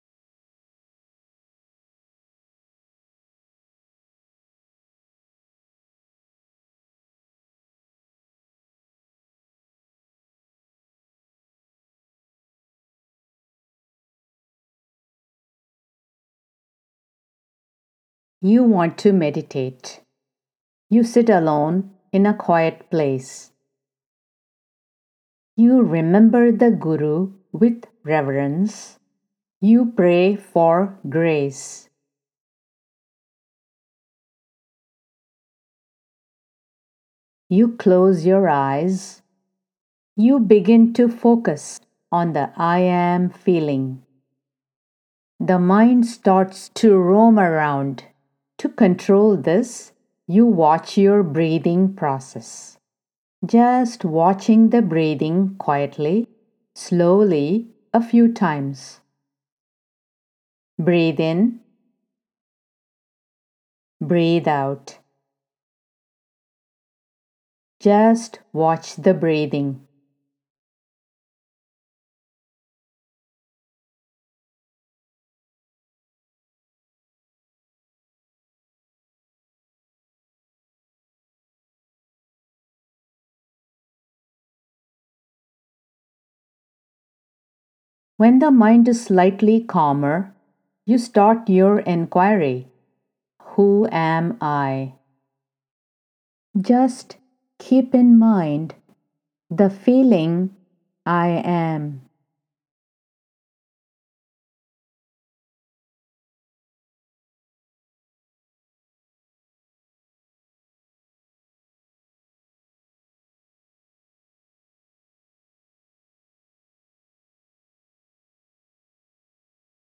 Guided Meditation
These Guided Meditation sessions contain a lot of Meditative Teachings of Nisargadatta Maharaj, offered once in a while, throughout the duration of the Video. Each teaching rendered is followed by a quiet time to practice that teaching.